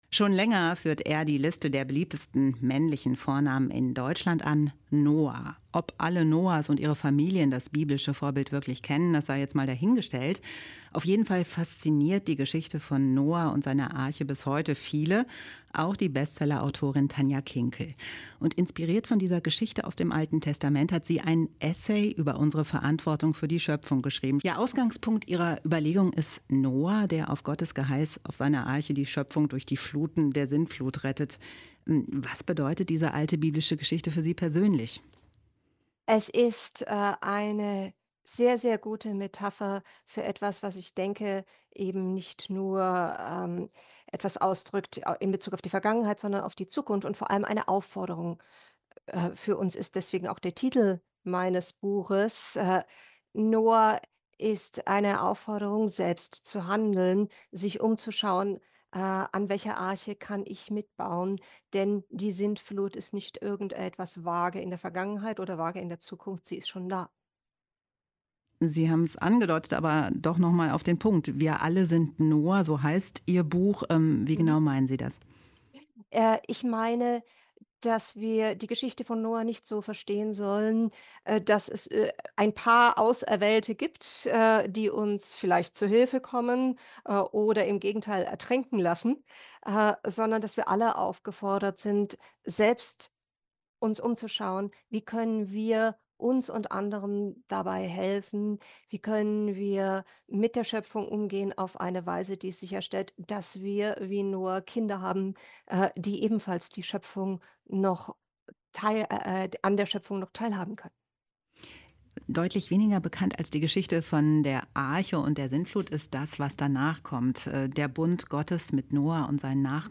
Ein Interview mit Tanja Kinkel (Bestseller-Autorin)